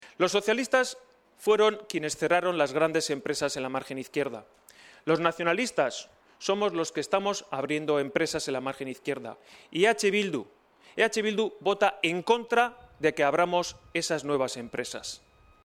“Que se abran empresas es la única manera que conozco de generar empleo, de generar actividad económica y de dejar atrás de una vez esta crisis”, asegura en los terrenos de Sestao donde PCB invertirá 20 millones en una nueva fábrica del siglo XXI que creará 200 puestos de trabajo.